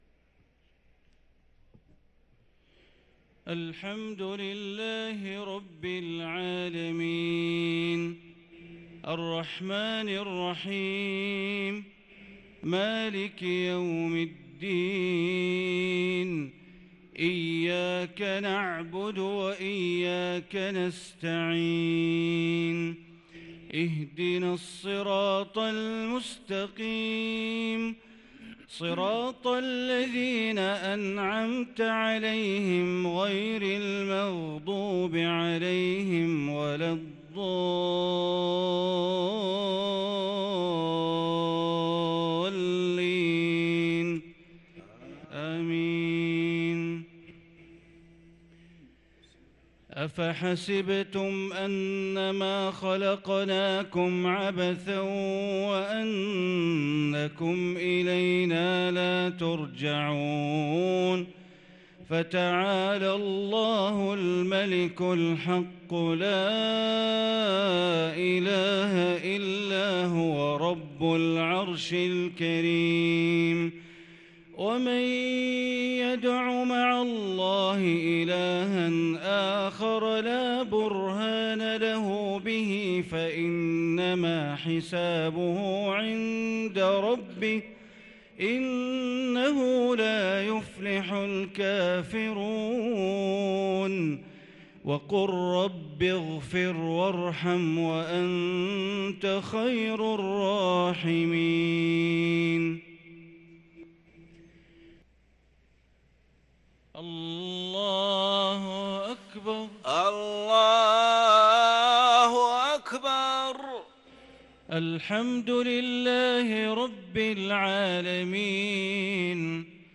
صلاة العشاء للقارئ بندر بليلة 10 رمضان 1443 هـ